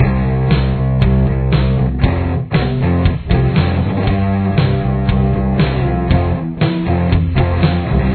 There are two guitars in this riff.